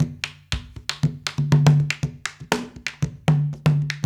120 -CONG0DR.wav